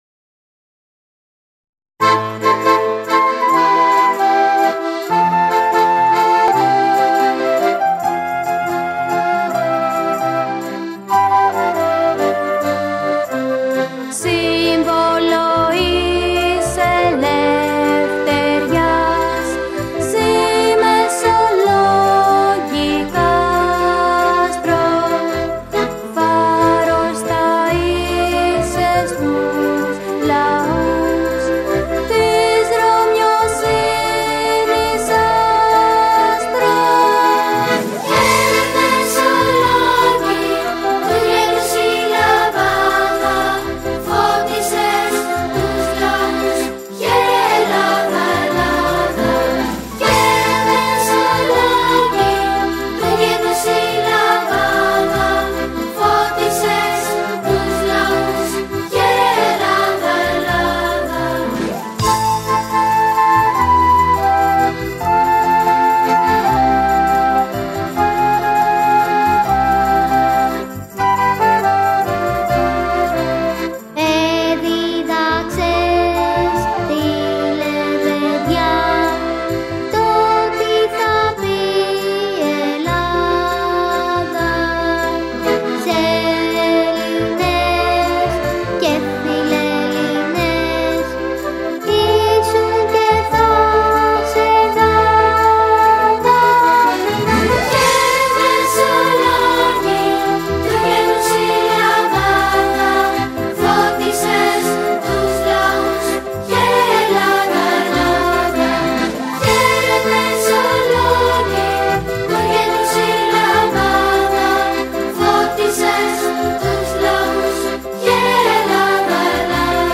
Cmaj